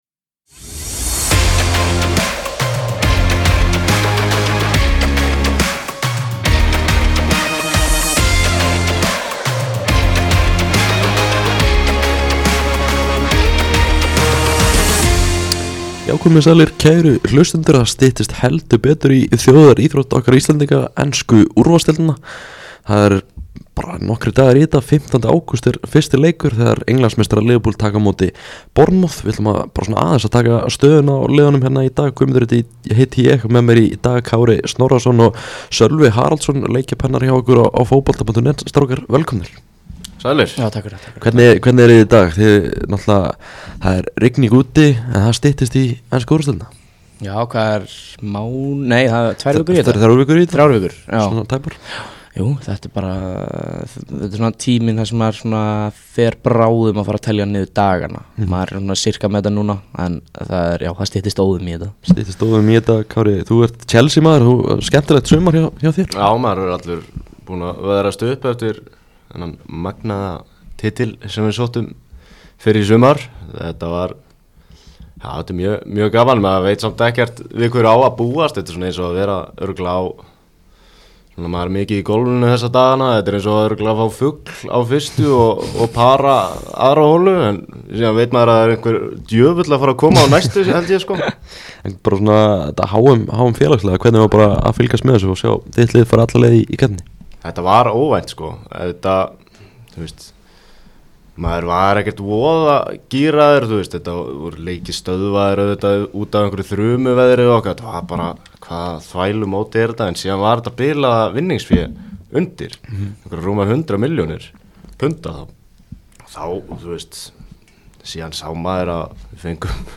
Pepsi Max stúdíóið